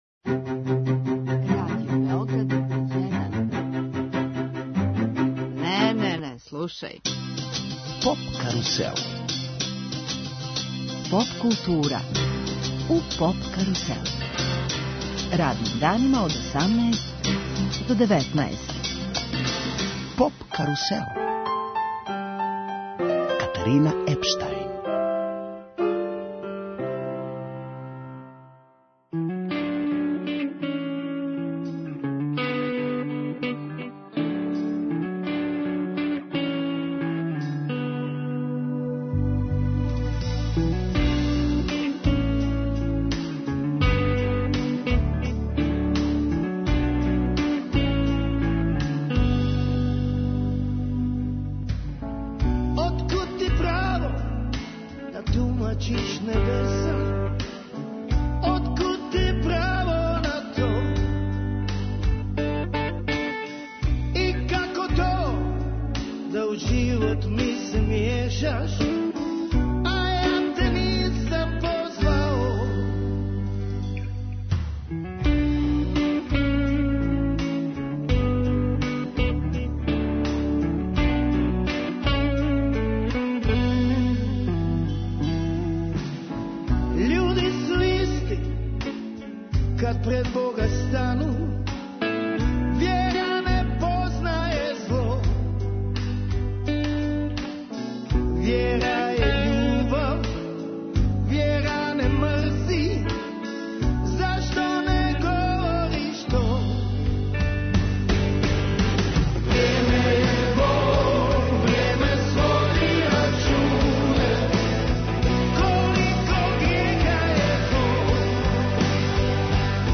Емитујемо ексклузивни интервју са саставом Парни ваљак, поводом великог београдског концерта.